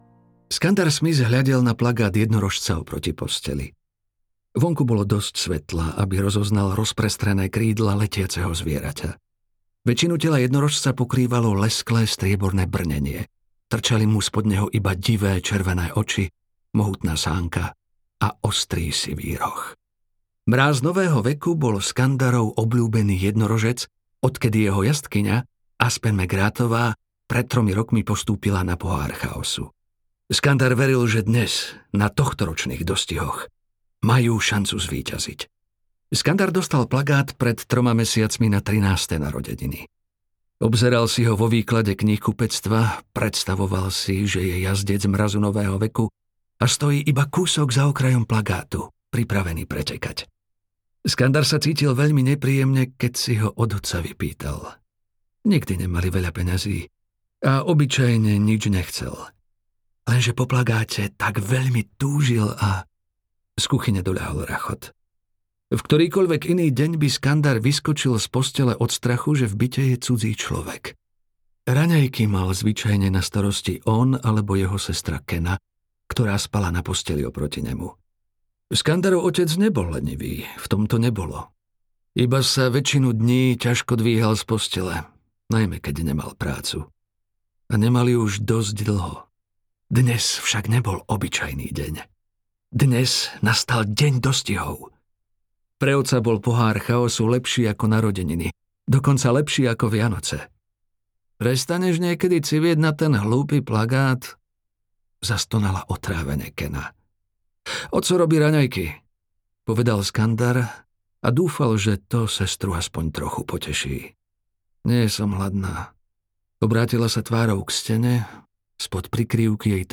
Skandar a zlodej jednorožcov audiokniha
Ukázka z knihy